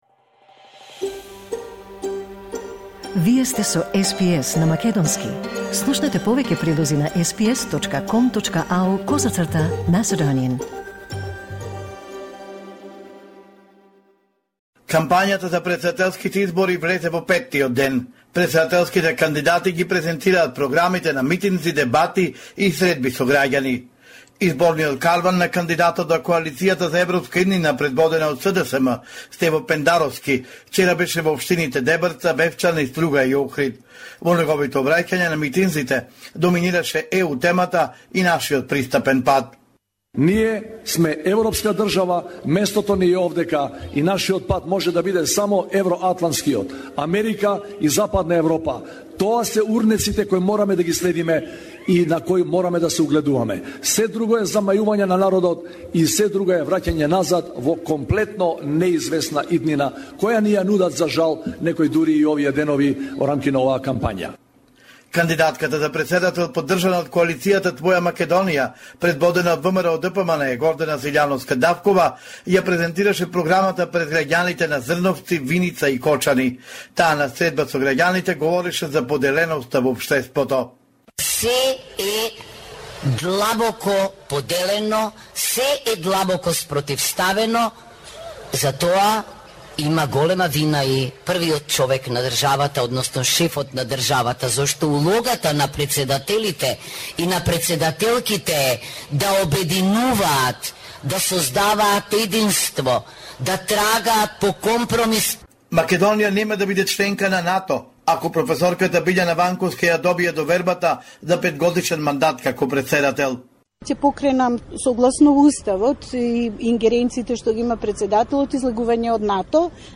Извештај од Македонија 8 април 2024
Homeland Report in Macedonian 8 April 2024